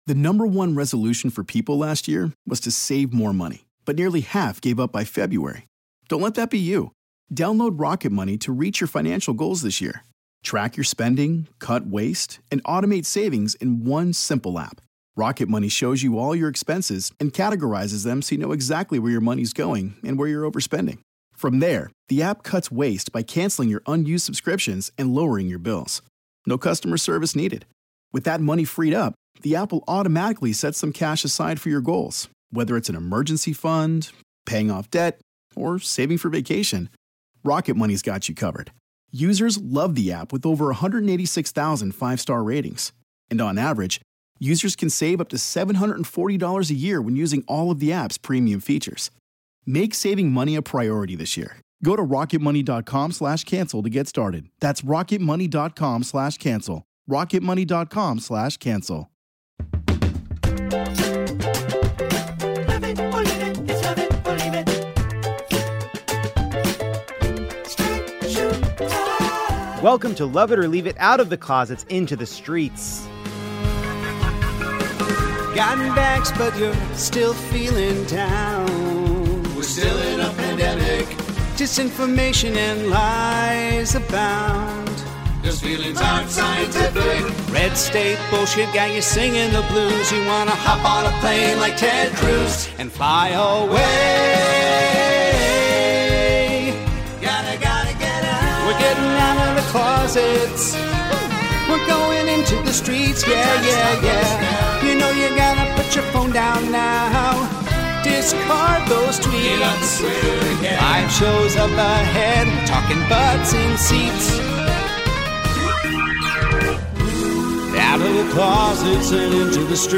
Rainn Wilson and Reza Aslan join to break down all the week's news, from Simon Biles's decision to Adam Driver's transformation. Jason Concepcion takes us through a bracket of former celebrity couples to see which reunion would reign supreme.